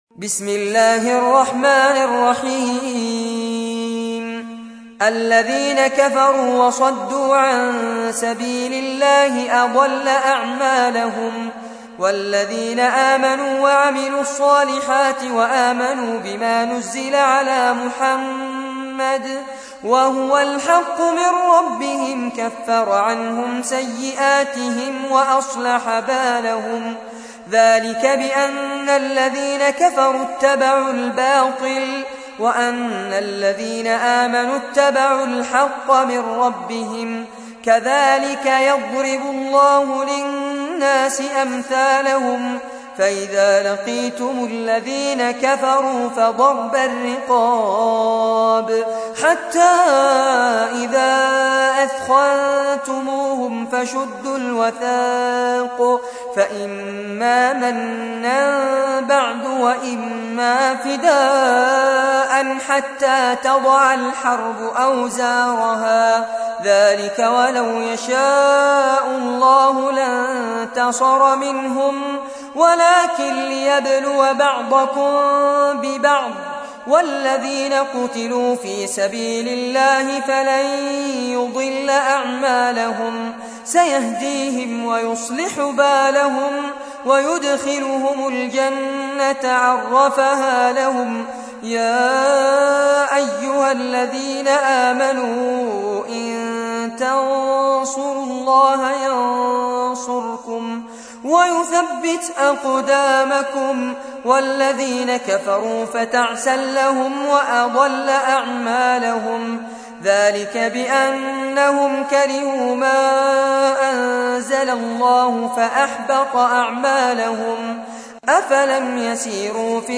تحميل : 47. سورة محمد / القارئ فارس عباد / القرآن الكريم / موقع يا حسين